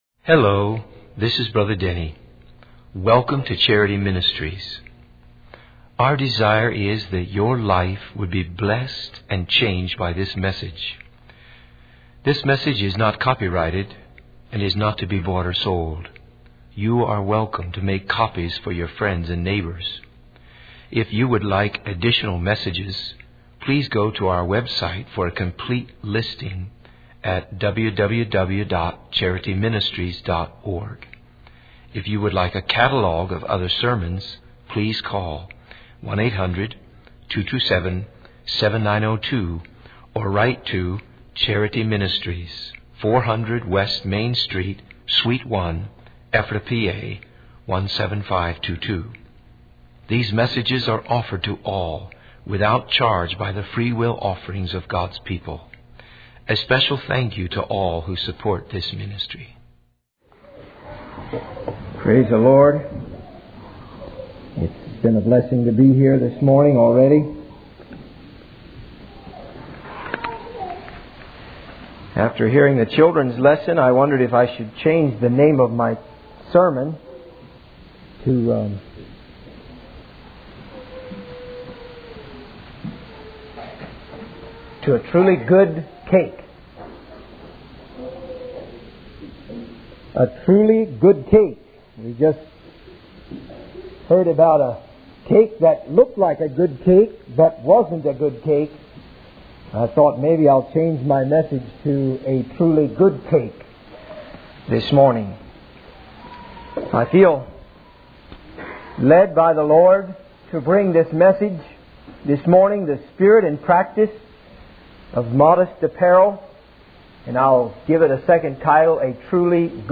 In this sermon, the preacher emphasizes the importance of the message conveyed by our clothing.